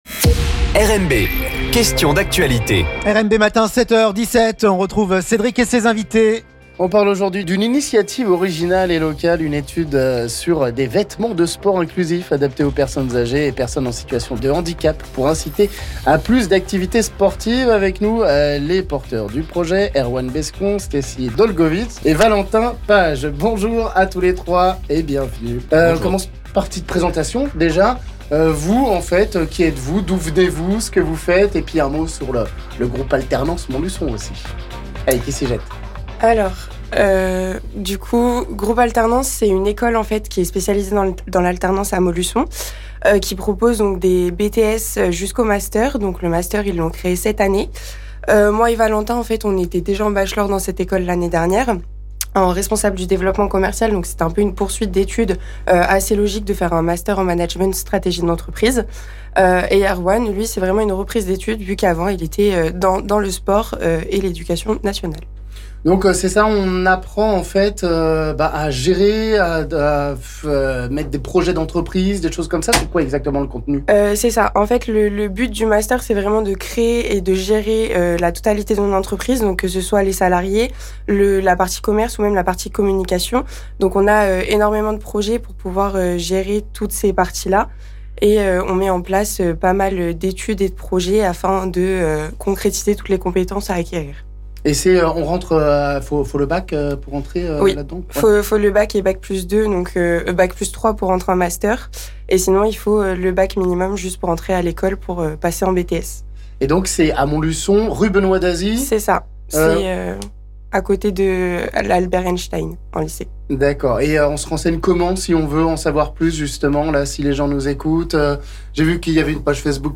On en parle avec les porteurs du projet